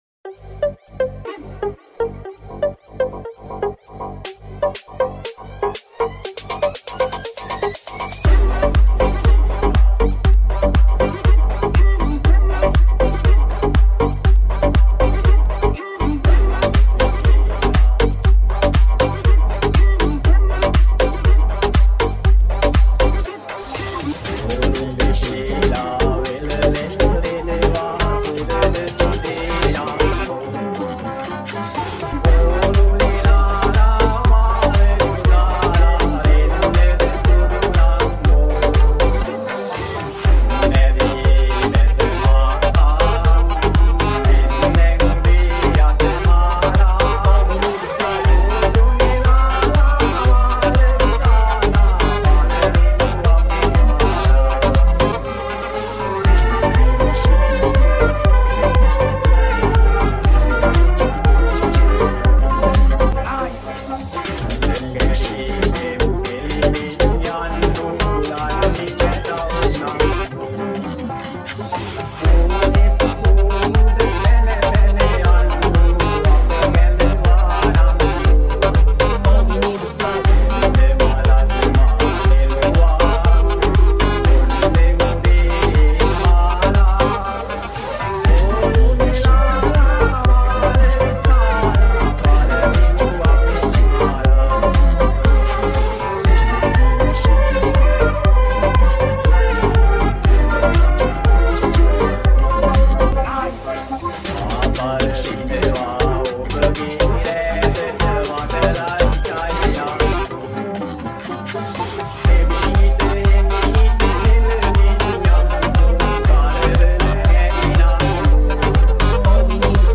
Old Sinhala Songs